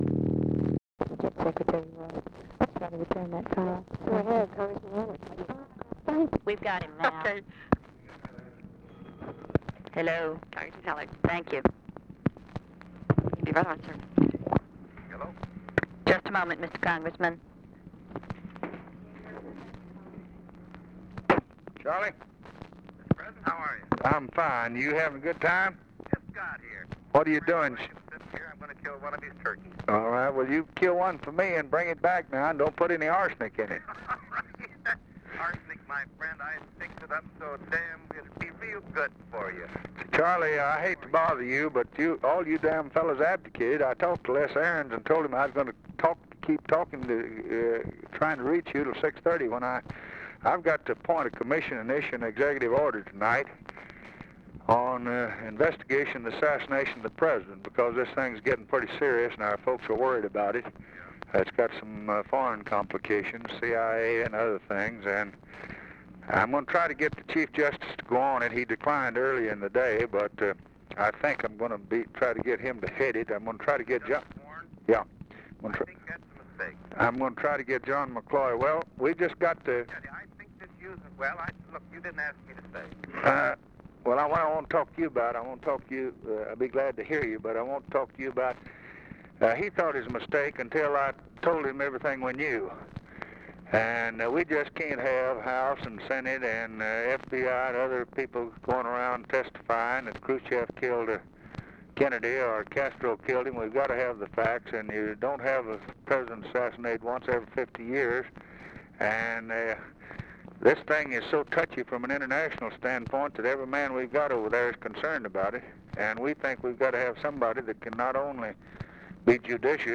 Conversation with CHARLES HALLECK, November 29, 1963
Secret White House Tapes